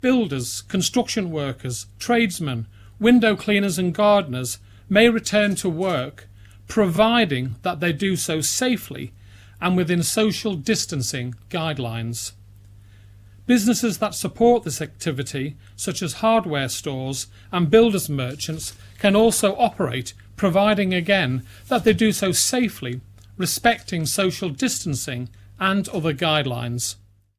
The Chief Minister Howard Quayle made the announcement in today's media briefing.
Mr Quayle says it has to be carried out carefully, or decisions can be reversed: